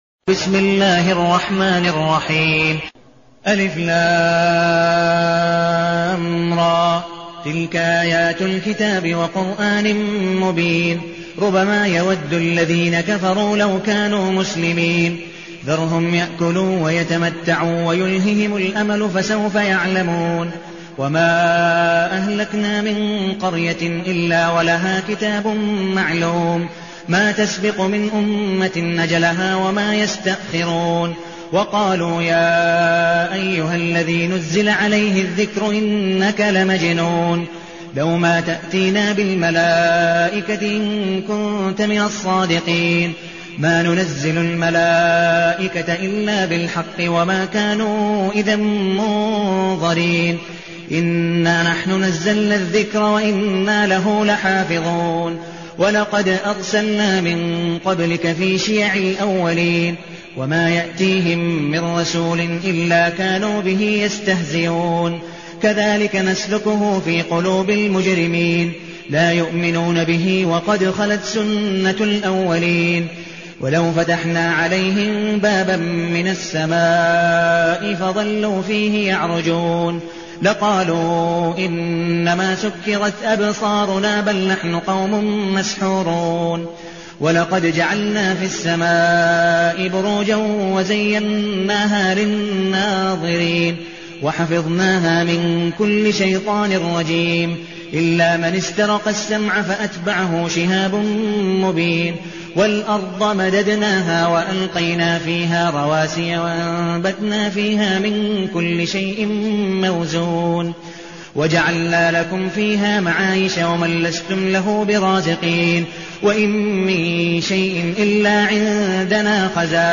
المكان: المسجد النبوي الشيخ: عبدالودود بن مقبول حنيف عبدالودود بن مقبول حنيف الحجر